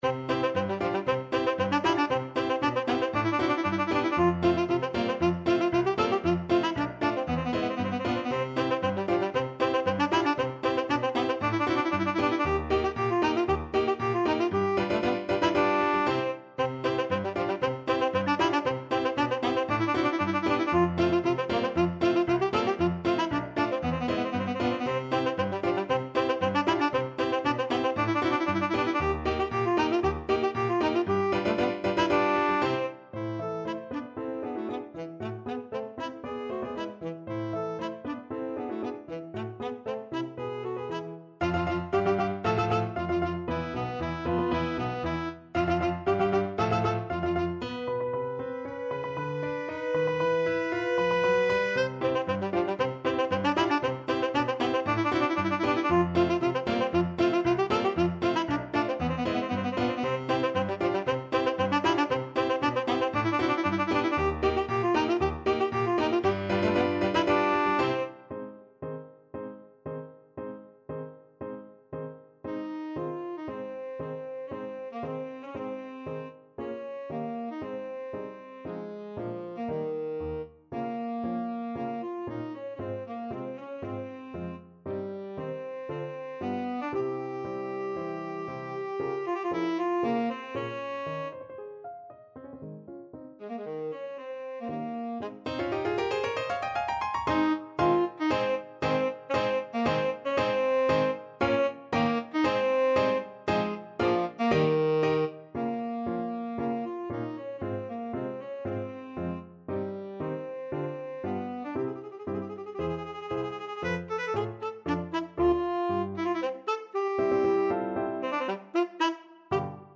Tenor Saxophone
C major (Sounding Pitch) D major (Tenor Saxophone in Bb) (View more C major Music for Tenor Saxophone )
Allegro giocoso =116 (View more music marked Allegro giocoso)
Classical (View more Classical Tenor Saxophone Music)
carmen_overture_TSAX.mp3